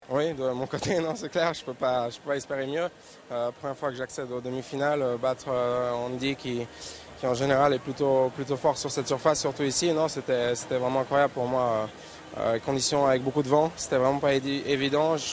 Voici à nouveau un sportif en interview : qui est-ce?